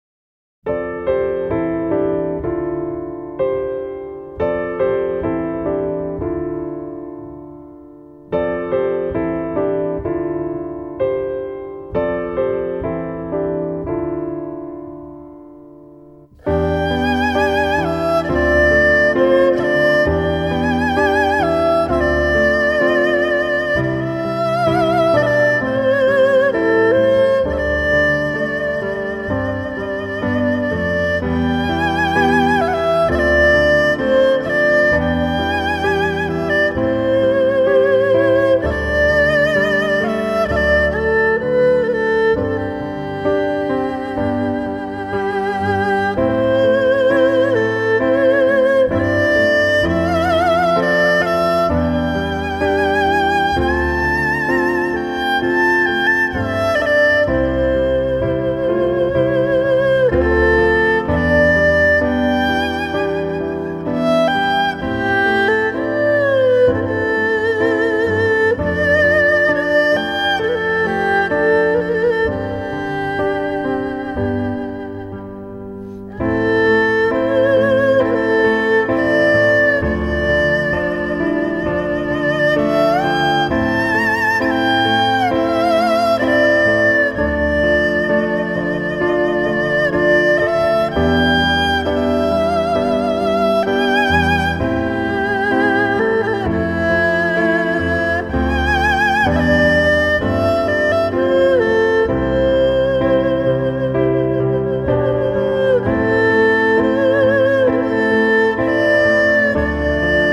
★ 融會貫通東西方樂器、傳統與現代的跨時代完美樂章！
★ 輕柔紓緩的美麗樂音，兼具令人震撼感動的發燒音效！